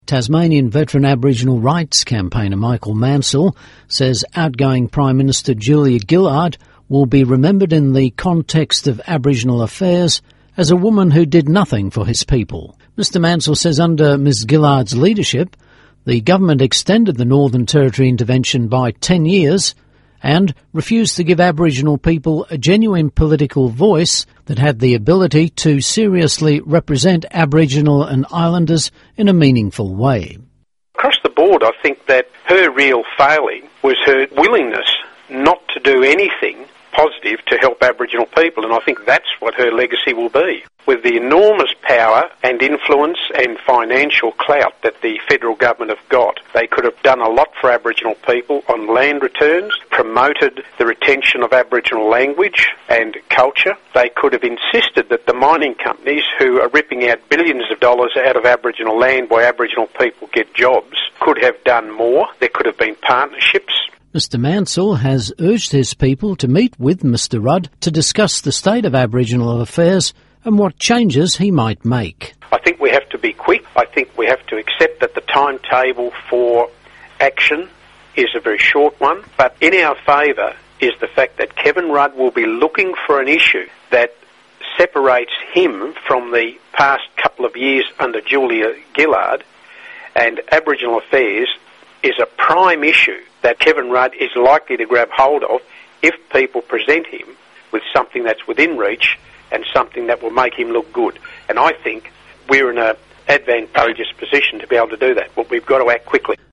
CAAMA Radio News